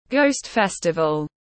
Ngày lễ Vu Lan tiếng anh gọi là Ghost Festival, phiên âm tiếng anh đọc là /gəʊst ˈfɛstəvəl/
Ghost Festival /gəʊst ˈfɛstəvəl/
Ghost-Festival-.mp3